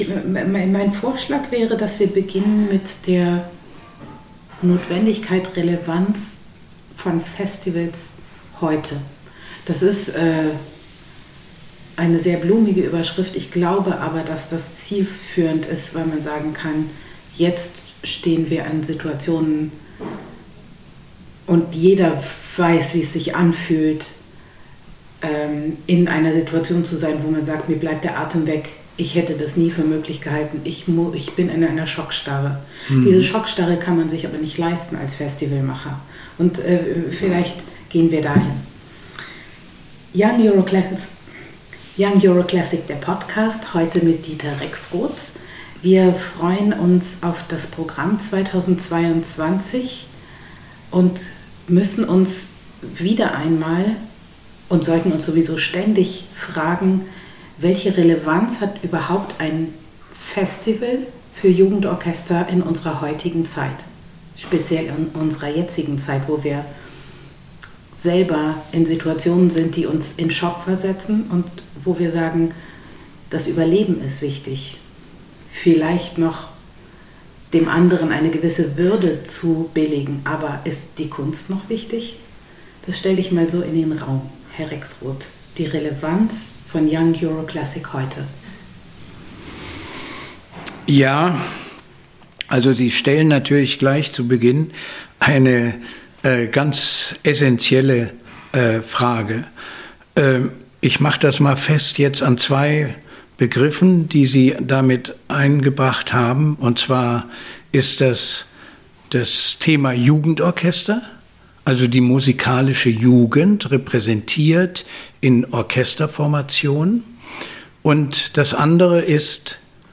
Saisongespräch 2022